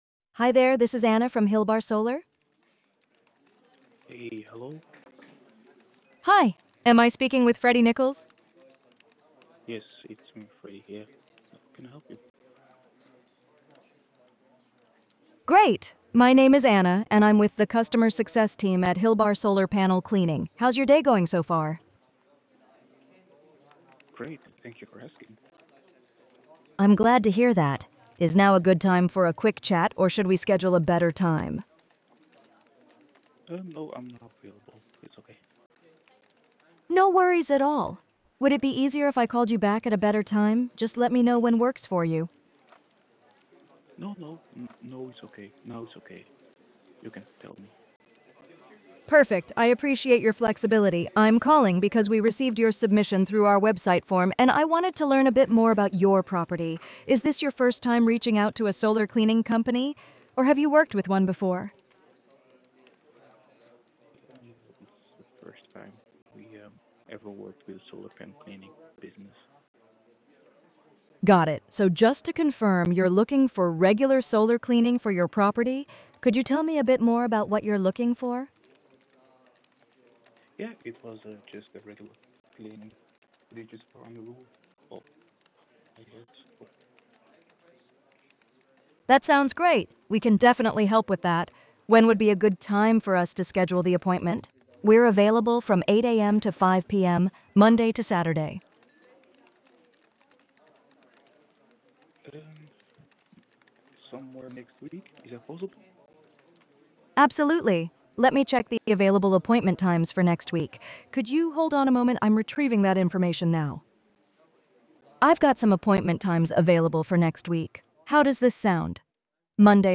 AI Voice Demo
AI Voice agent Audio
A quick demo of the voice agent used in Hilbar Solar’s cleaning division built to automate calls, support customers, and book appointments as part of their scaling case study.